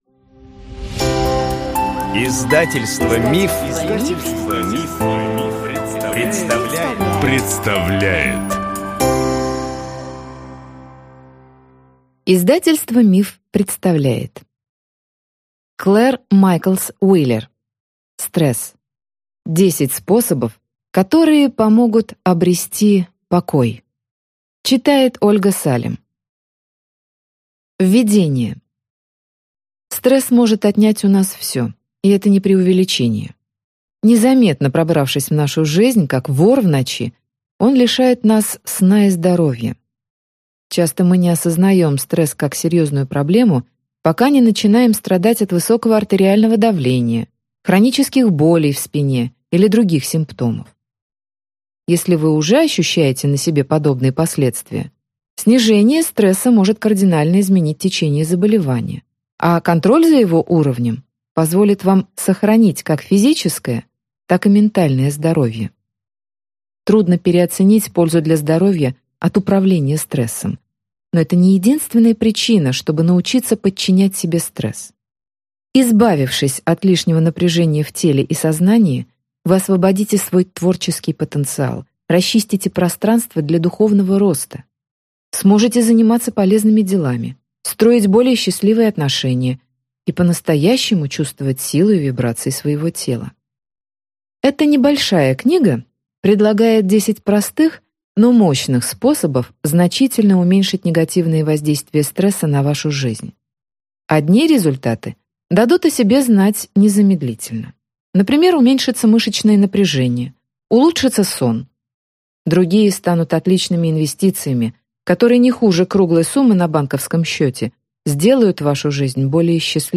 Аудиокнига Стресс. 10 способов, которые помогут обрести покой | Библиотека аудиокниг